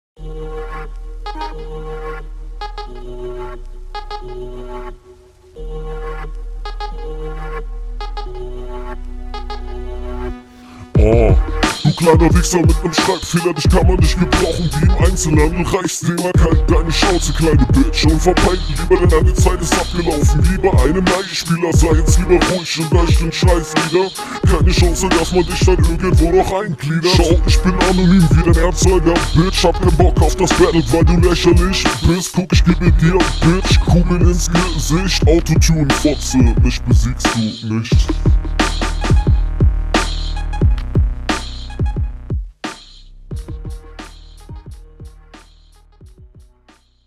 Flow: an sich gut aber man versteht kaum was wegen der tief gepitchten vocal Text: …
Flow: On Beat aber keine guten Flowvariationen bei Sekunde 31-34 klang das etwas holprig und …